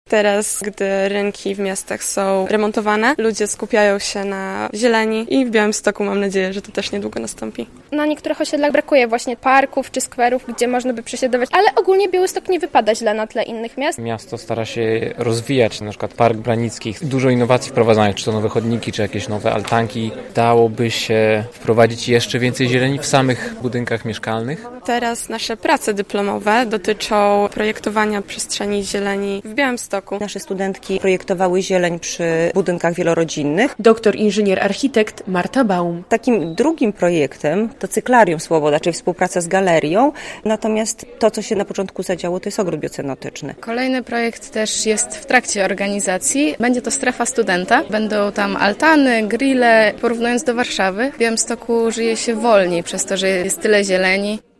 Dzień Architektury Krajobrazu - relacja